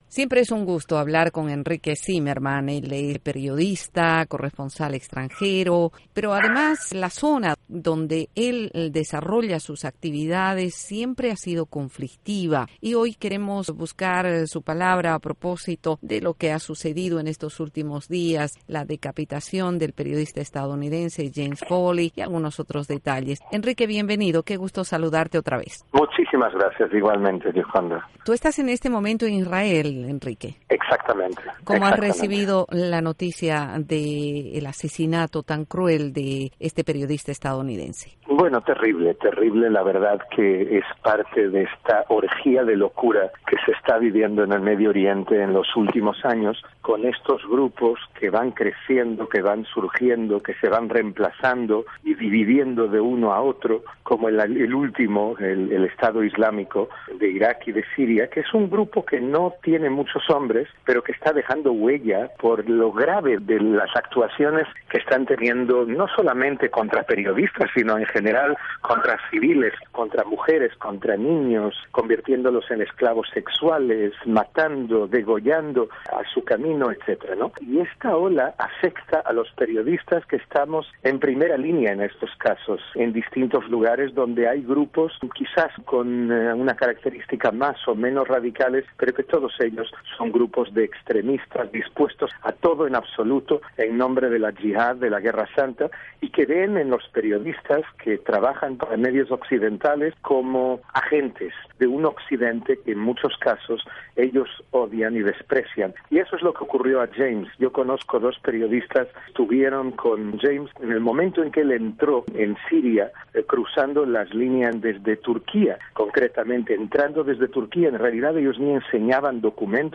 Entrevista a Henrique Cymerman desde Medio Oriente